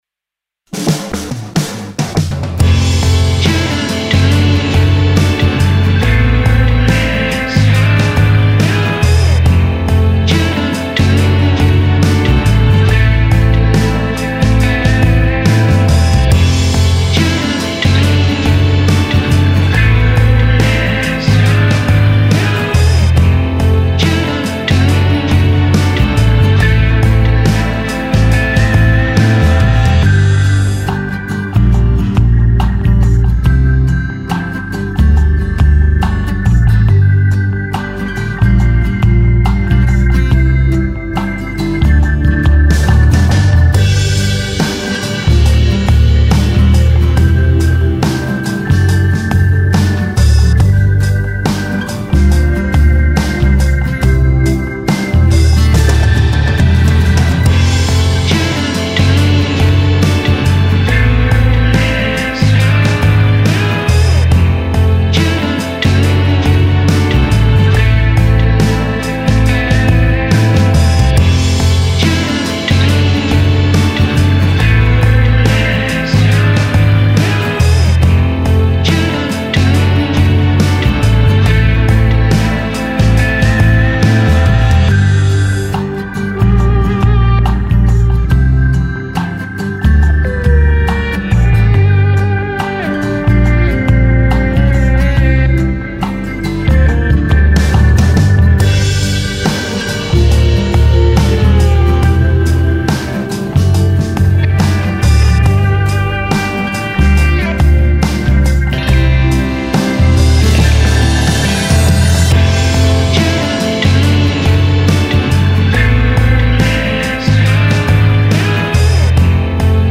aerien - pub - sport - riffs - rock